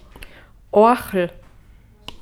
Oachl / Begriff-ABC / Mundart / Tiroler AT / Home - Tiroler Versicherung